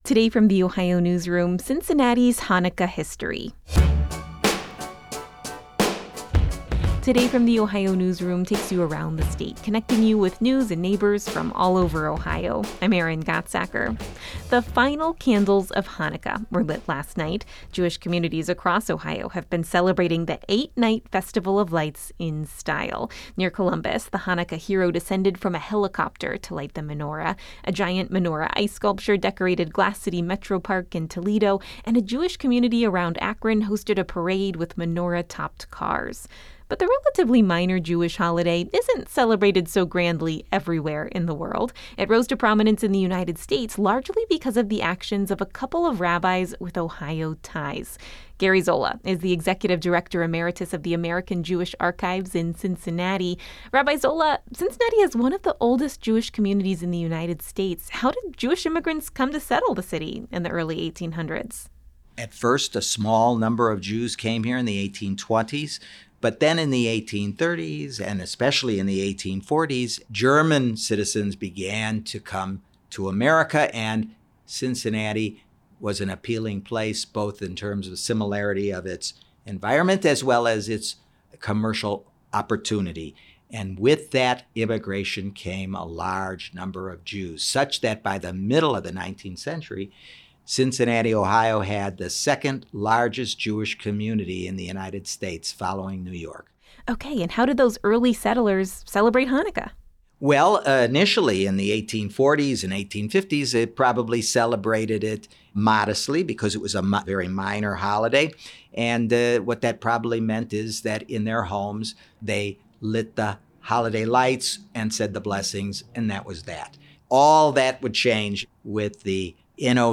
This conversation has been lightly edited for clarity and brevity.